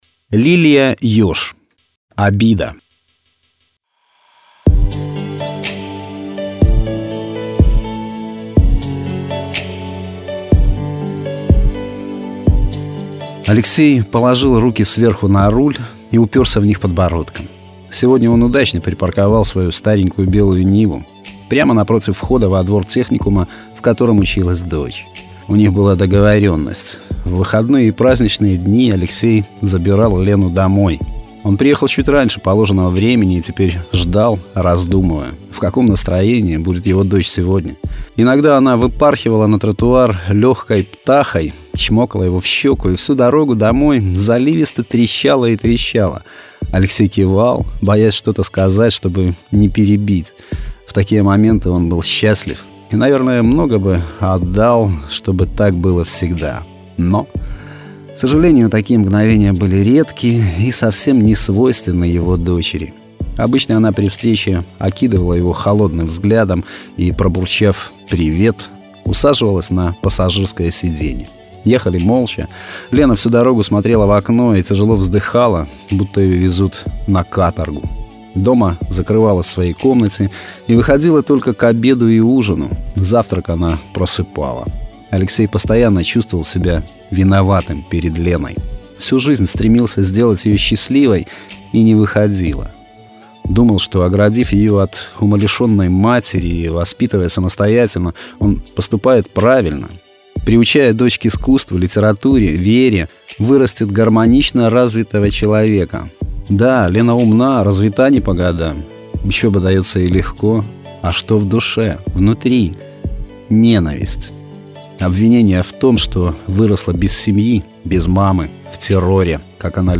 Жанр: Современная короткая проза